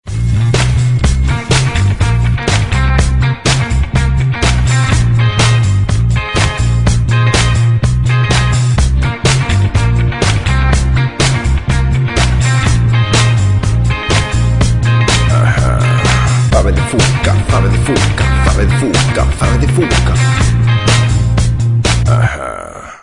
Gran anuncio de televisión.